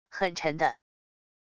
很沉的wav音频